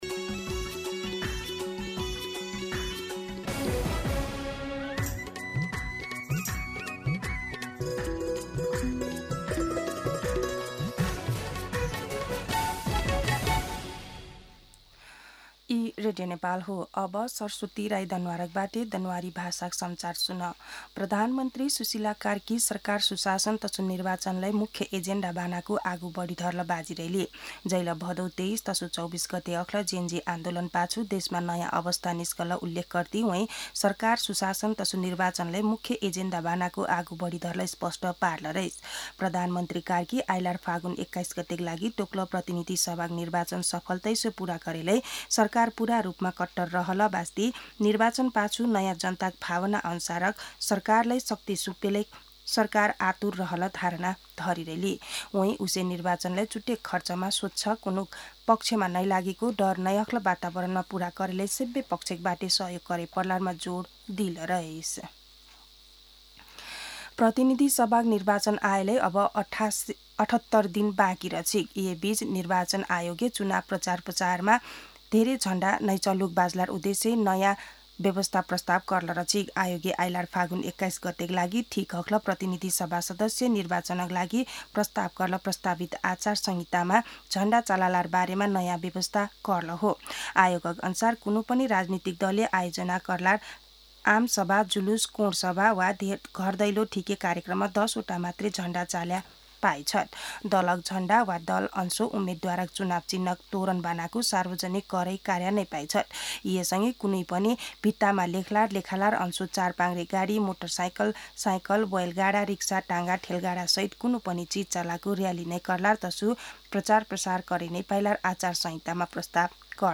दनुवार भाषामा समाचार : २ पुष , २०८२
Danuwar-News-9-2.mp3